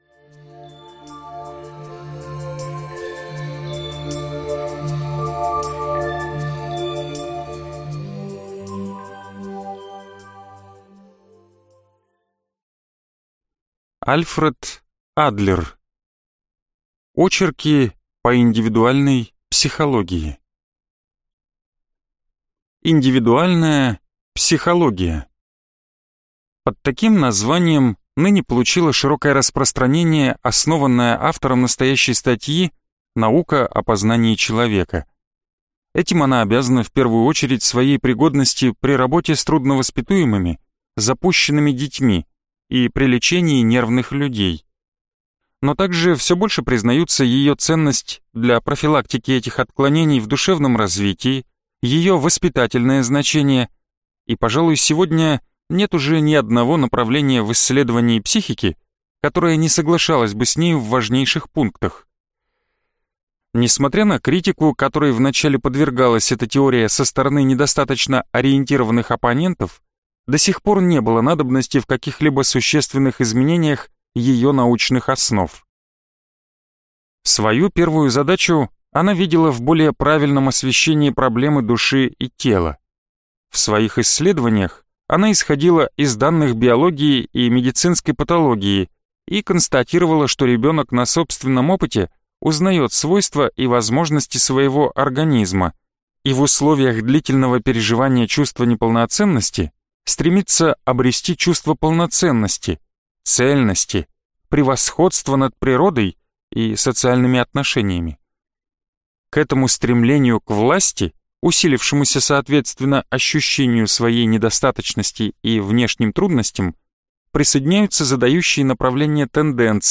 Аудиокнига Очерки по индивидуальной психологии | Библиотека аудиокниг